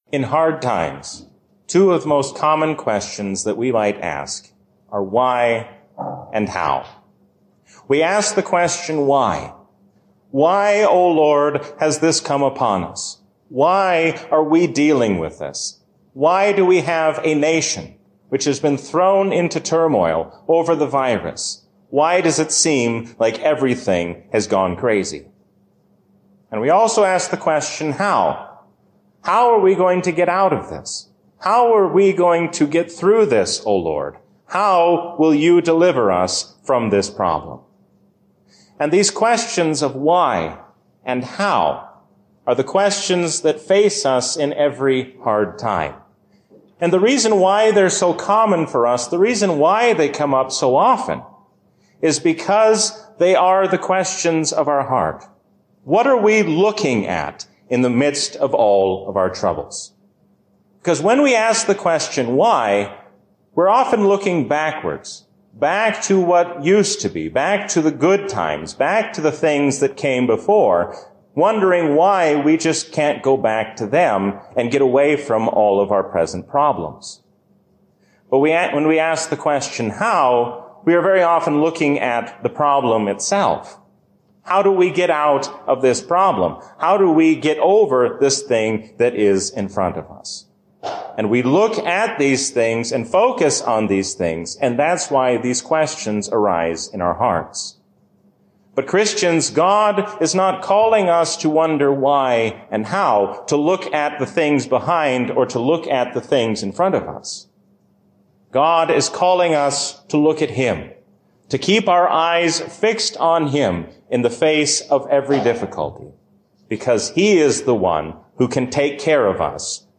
A sermon from the season "Trinity 2024." The ascension of Christ strengthens us to carry out the work of the Church while we wait for His coming.